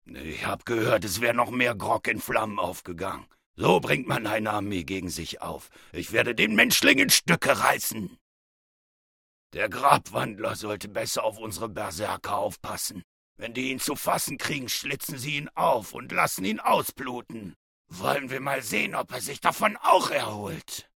Stimmproben Jan Sosniok